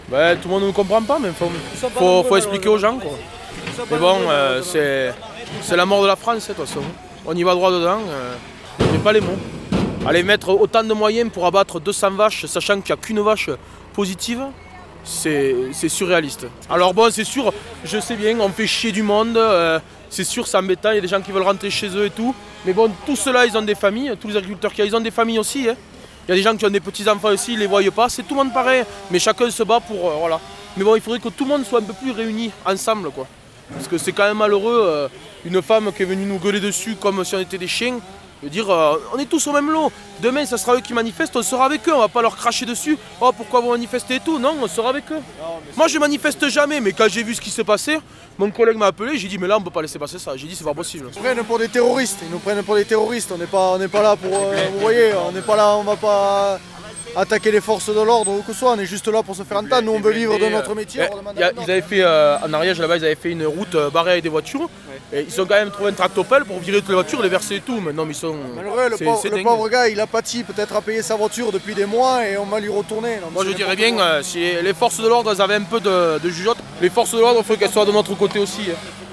» Les premières réactions sur place
Au total, une centaine d’agriculteurs ont passé la nuit sur le rond-point de l’échangeur de l’autoroute vers la D809. Une manifestation en réponse à l’abattage total d’un troupeau atteint par la dermatose nodulaire contagieuse dans l’Ariège.